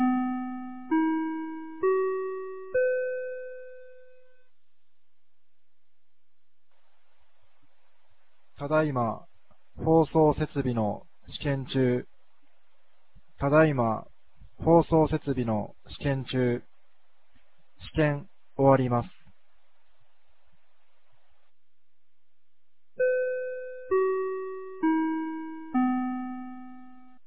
2025年07月19日 16時03分に、由良町から全地区へ放送がありました。